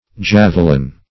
Javelin \Jave"lin\, v. t.